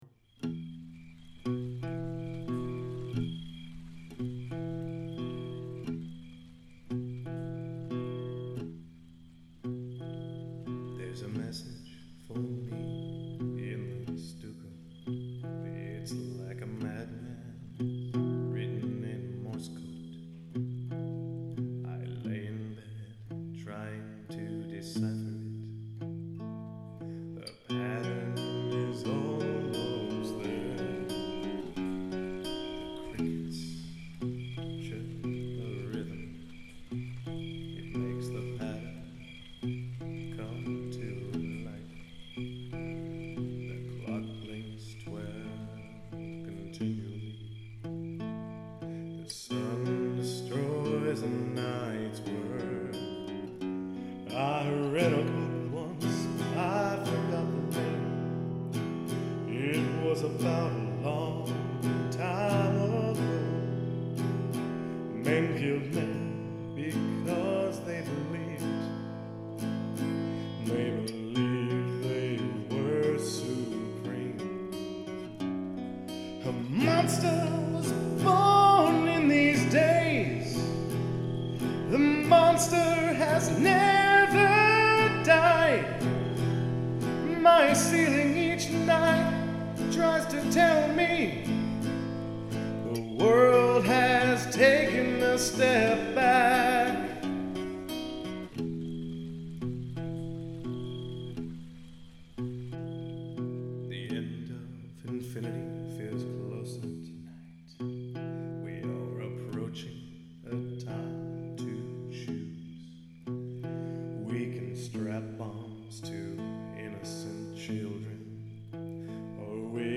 This is the initial version of what will be the opening number in An Unsafe Place: A Love Story. I have been having some discussions about whether to call this a musical or rock opera.